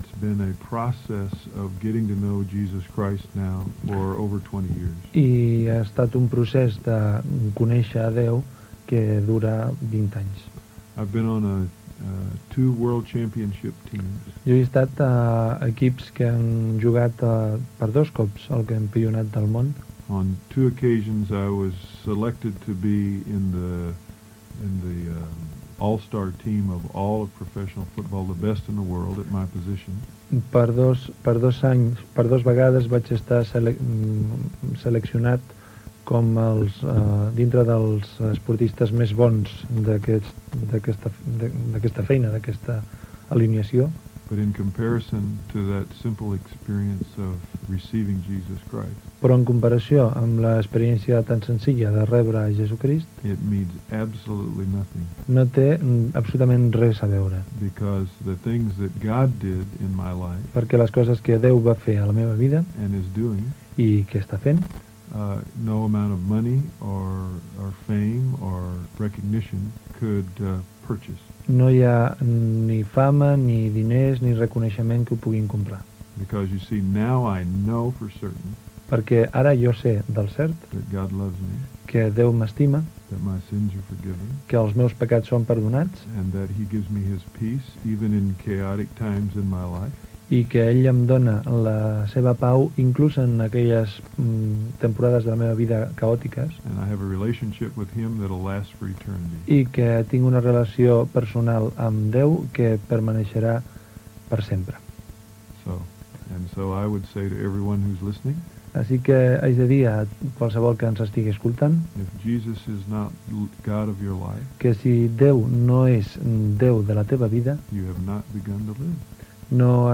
6a277e068d160a9571fbba20b4ac8b1c10744e07.mp3 Títol Ràdio Bona Nova Emissora Ràdio Bona Nova Titularitat Tercer sector Tercer sector Religiosa Descripció Testimoni d'un esportista nord-americà. Gènere radiofònic Religió